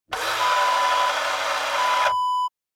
Car Side Mirror Adjust Wav Sound Effect #3
Description: The sound of adjusting automobile side view mirror
Properties: 48.000 kHz 24-bit Stereo
A beep sound is embedded in the audio preview file but it is not present in the high resolution downloadable wav file.
Keywords: car, auto, automobile, side, sideview, side-view, mirror, mirrors, adjust, adjusting, motor, servomotor, move, moving, movement
car-side-mirror-adjust-preview-3.mp3